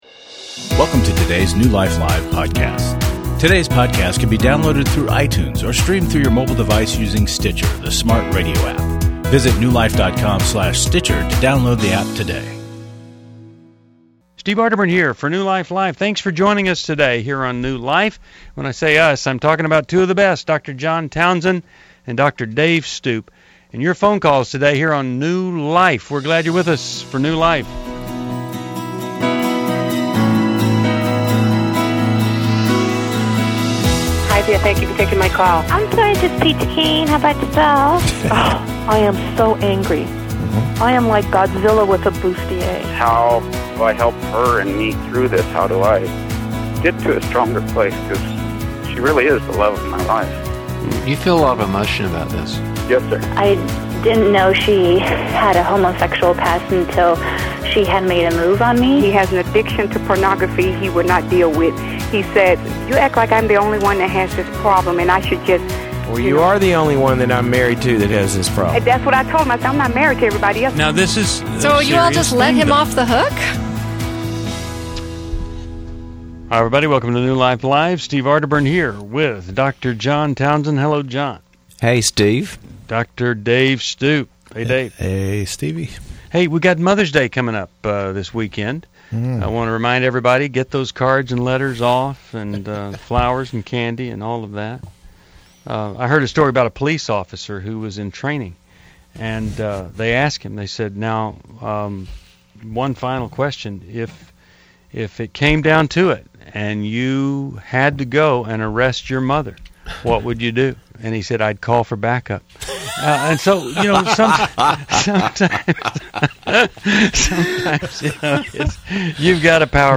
Caller Questions: My husband had an affair; can I divorce him biblically?